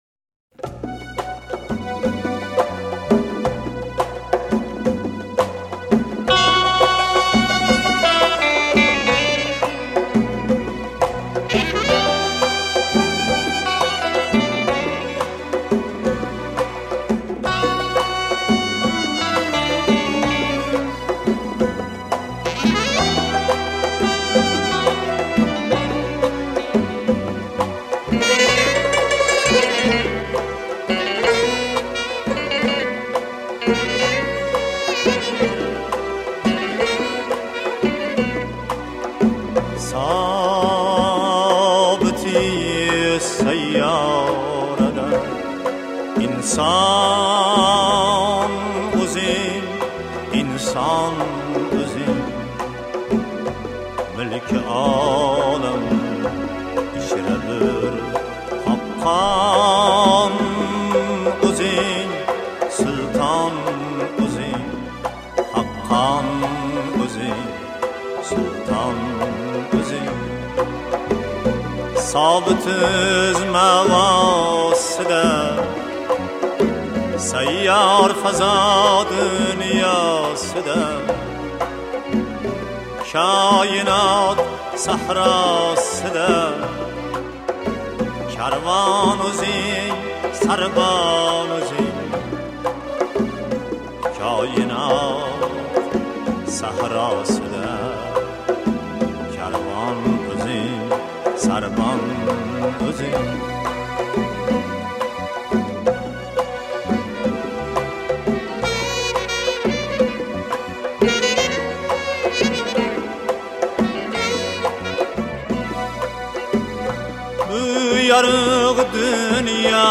Ўзбекистон мусиқаси